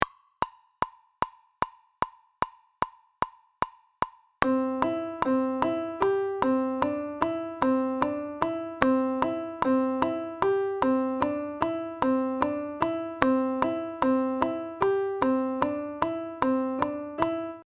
Ejemplo de compás de 11x8 con la medida de 2+3+3+3.
Audio de elaboración propia. Subdivisión métrica del compás 11x8 en 2 + 3 + 3 + 3. (CC BY-NC-SA)
COMPAS-11x8-2.mp3